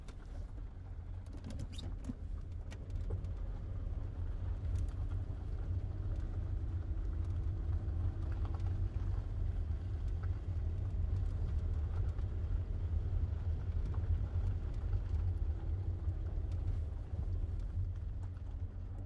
Engine going from inside a vehicle
Duration - 19 s Environment - Friction, cliattering of dash board, slight vibration of mike movements. Description - Engine, throttling, moving, humming, slows down